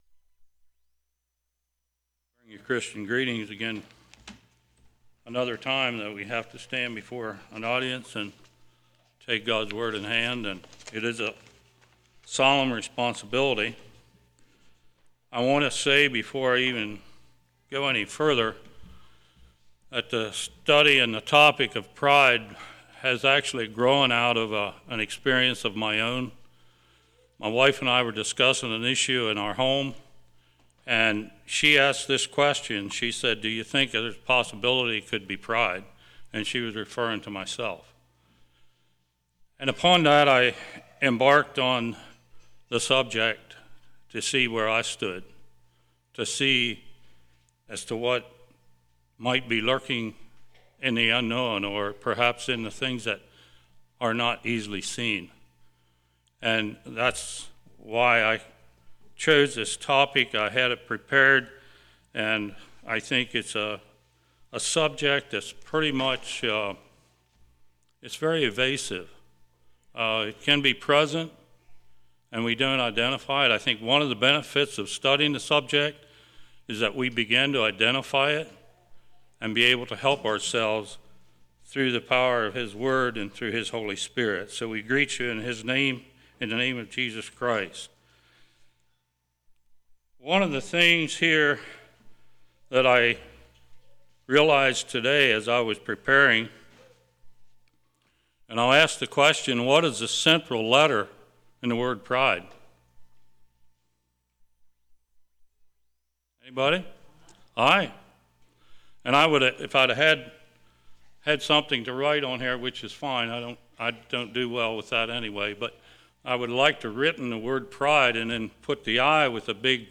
Service Type: Winter Bible Study